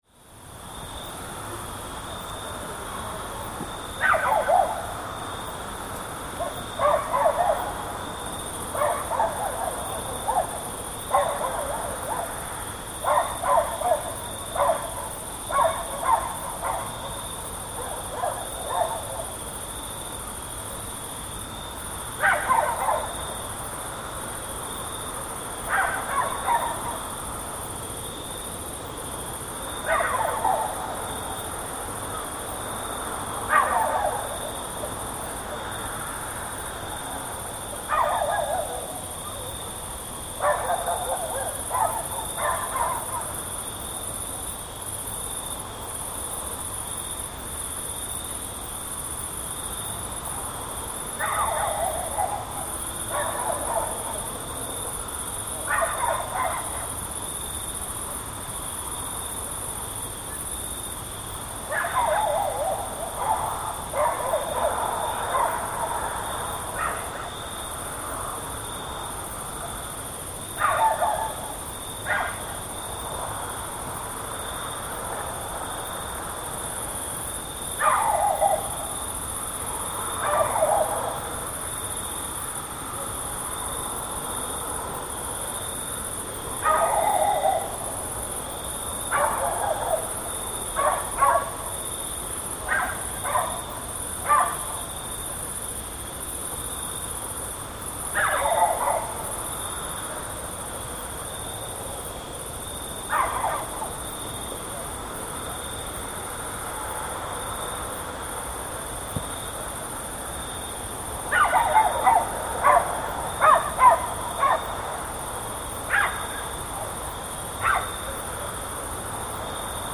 Crickets-dogs-wind-night-ambient-sound-effect.mp3